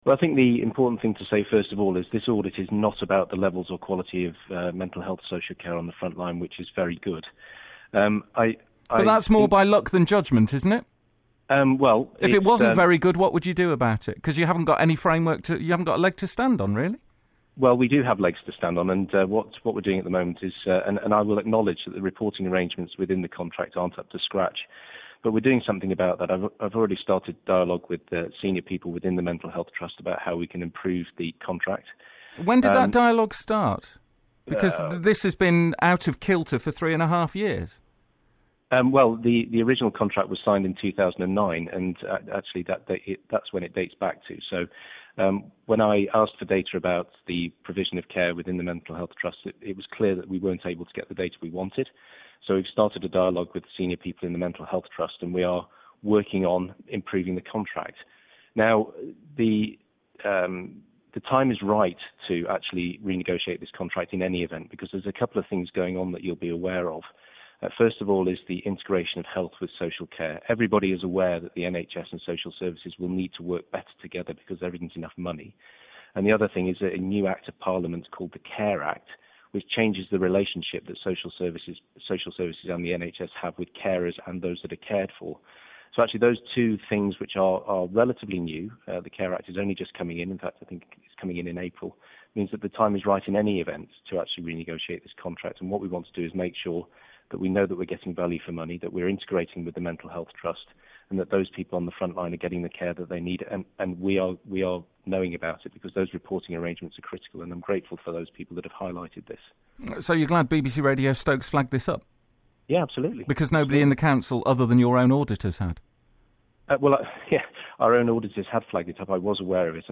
Interview with Conservative cllr Alan White from Staffs County Council